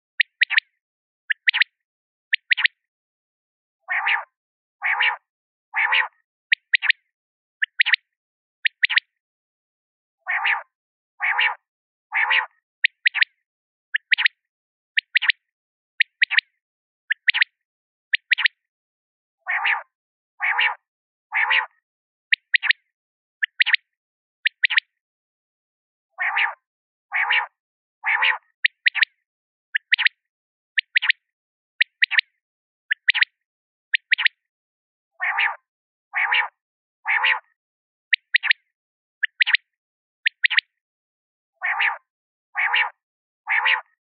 Wachtel Geräusche
• Ihr typischer Ruf klingt wie „pick-per-wick“ – besonders in warmen Sommernächten.
Typisch ist der dreisilbige Ruf „pick-per-wick“, meist in der Dämmerung.
Wachtel-Geraeusche-Voegel-in-Europa.mp3